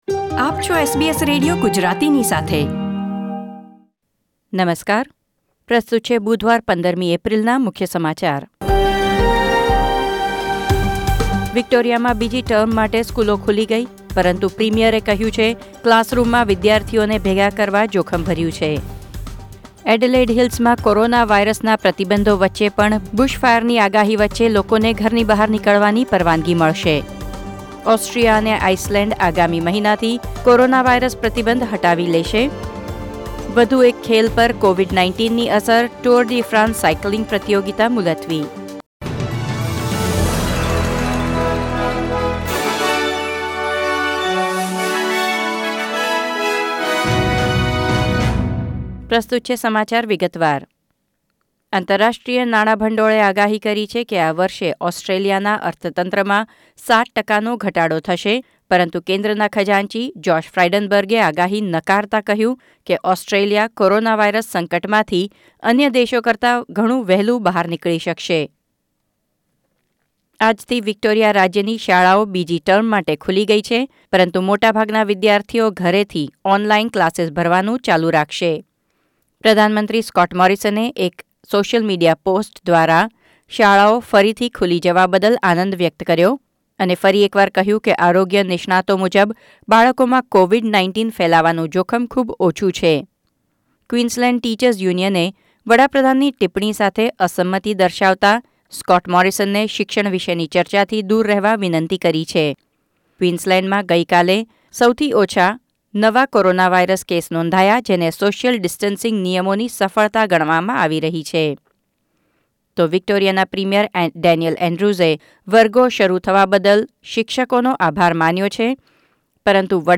૧૫ એપ્રિલ ૨૦૨૦ ના મુખ્ય સમાચાર